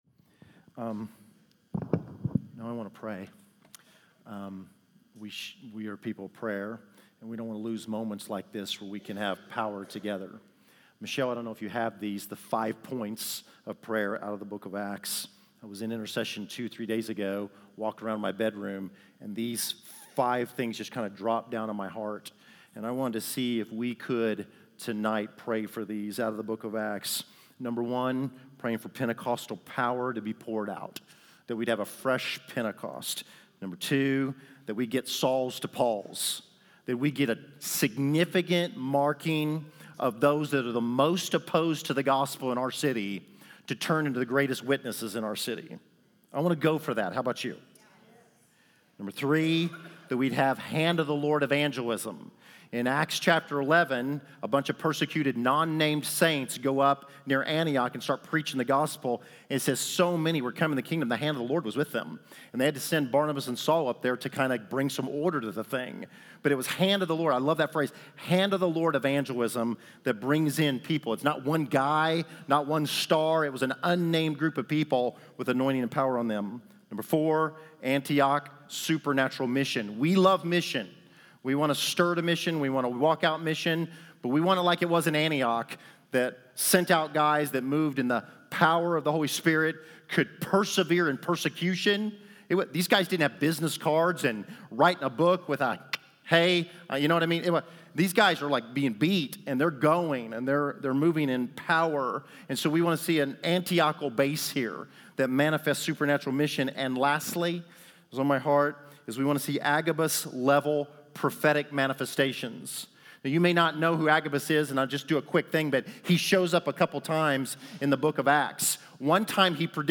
December 28, 2019      Category: Teachings      |      Location: Wichita